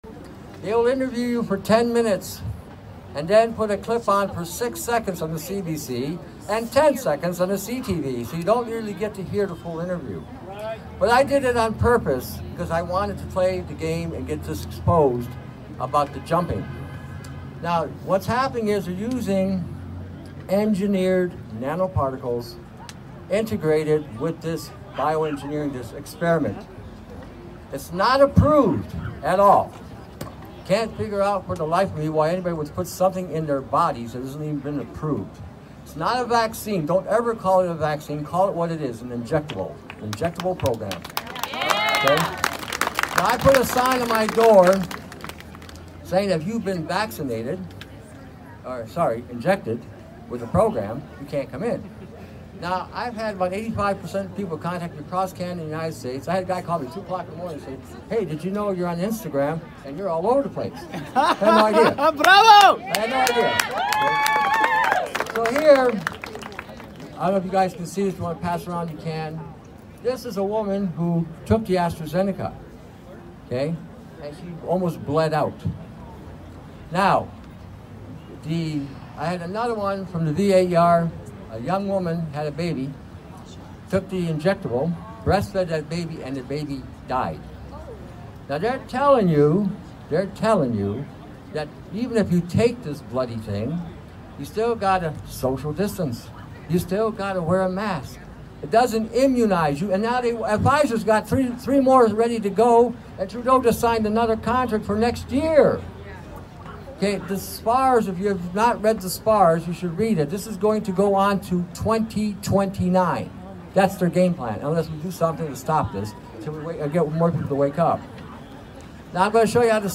Peaceful Gathering Windsor Ontario 🇨🇦